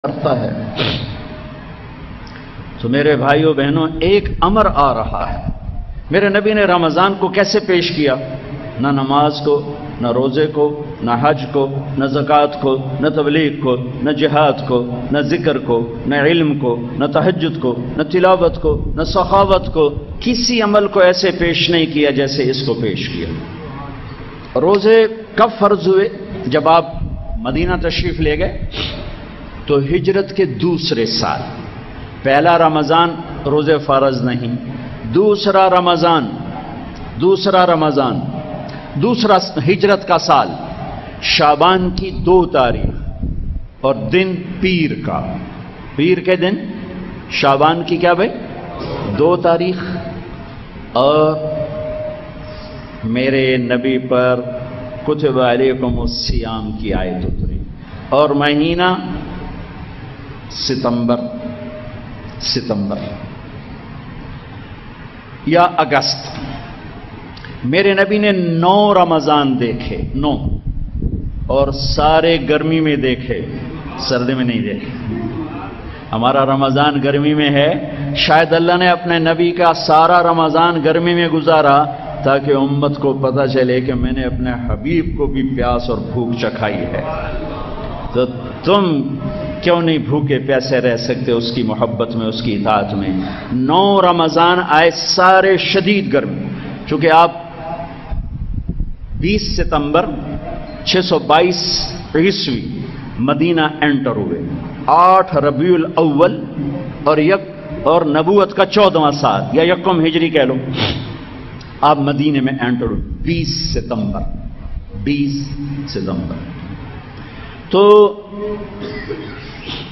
Ramzan Special Bayan Maulana Tariq Jameel bayan
Maulana-tariq-jameel-bayan-about-ramzan.mp3